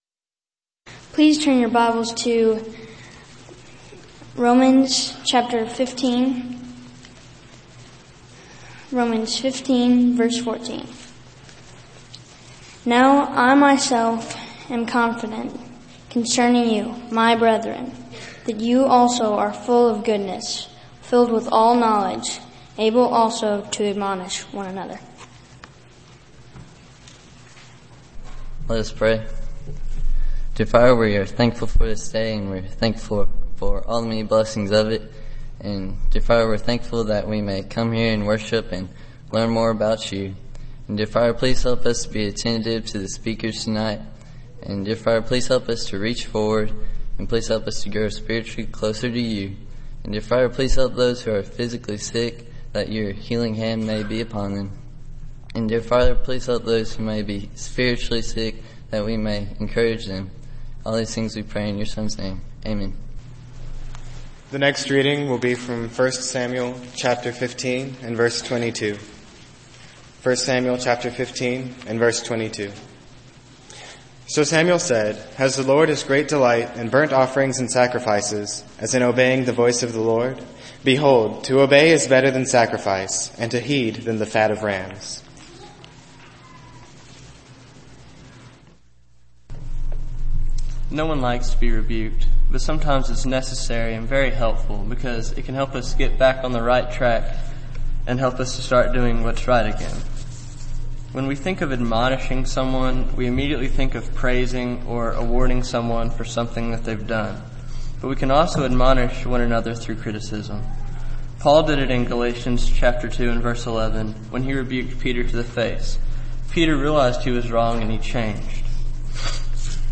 Service: Sun PM Type: Sermon Speaker: Various Young Men